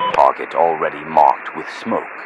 Radio-jtacSmokeAlreadyOut5.ogg